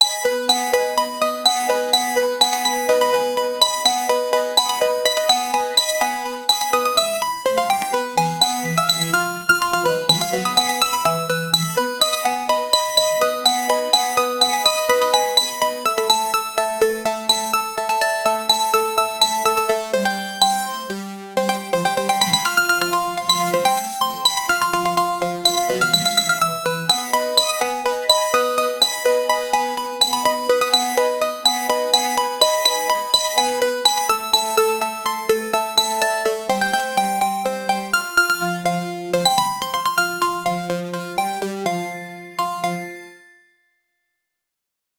Make Super Electric Cutes in computer sound Super SPECIAL Amazing Song RPG Game Pixel SPECIALIST!! But Calm...and Motivational That's million% will loved it ❤‍🔥👾 The best Song RPG Game In The World..
make-super-electric-cutes-rrueo3ft.wav